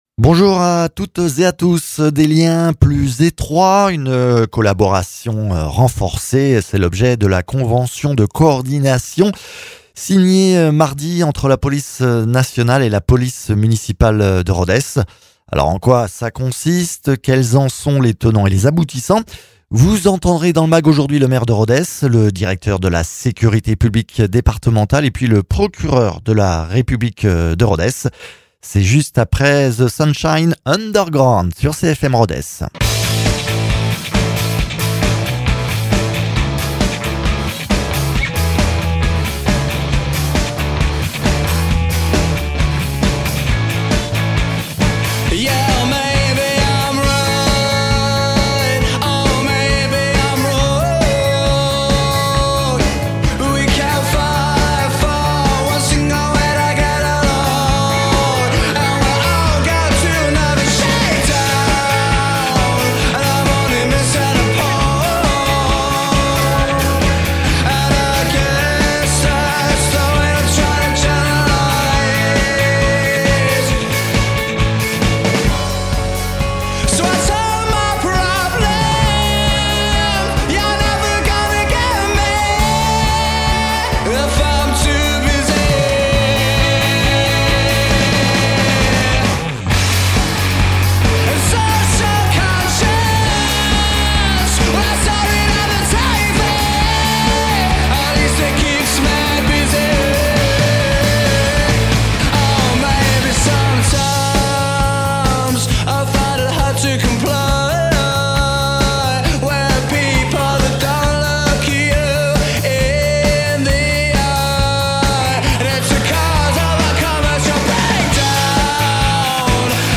Invité(s) : Loïc Jézéquel, Directeur départemental de la sécurité publique de l’Aveyron ; Christian Teyssèdre, Maire de Rodez ; Olivier Naboulet, Procureur de la République de Rodez.